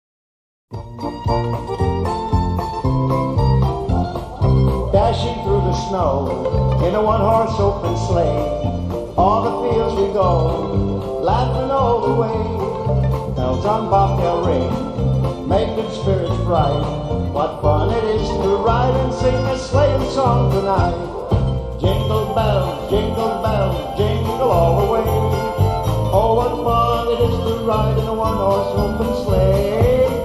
Genre: Christmas